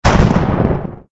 lightning_2.ogg